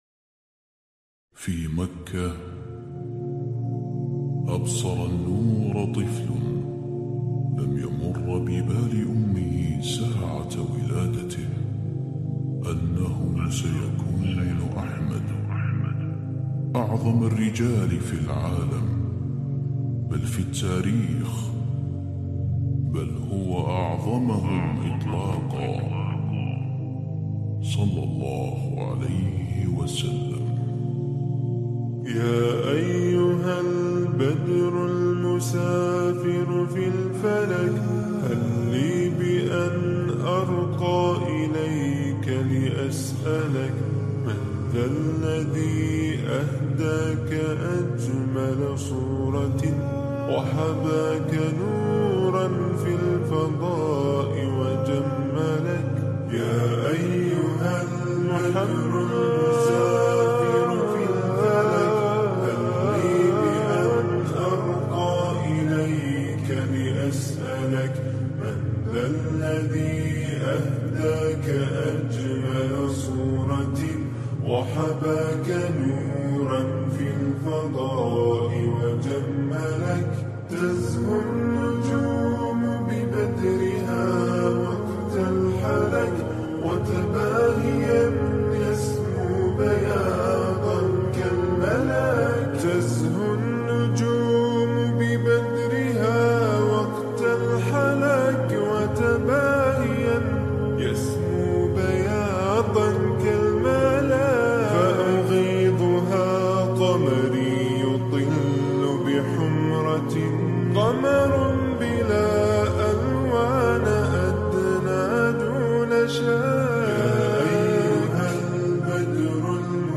Enjoy this peaceful Islamic Nasheed.